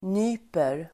Uttal: [n'y:per]